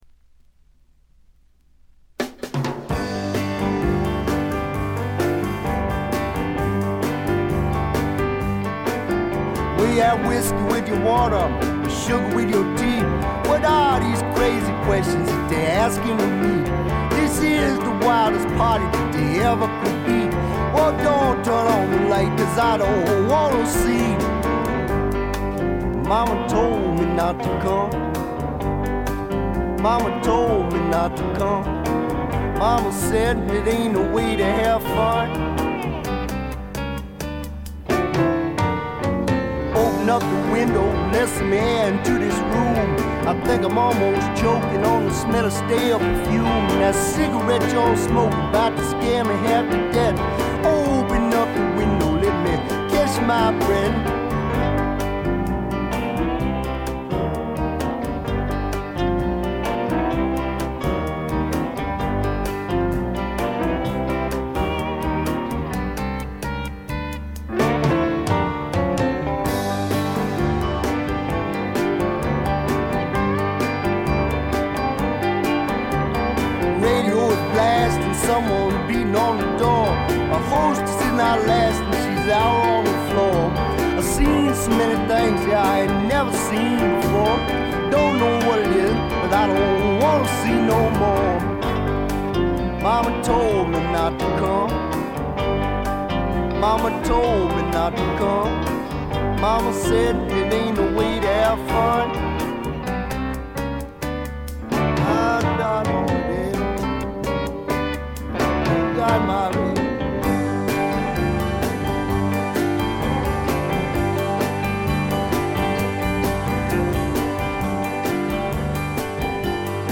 静音部で軽微なバックグラウンドノイズが少し。
試聴曲は現品からの取り込み音源です。
vocals, piano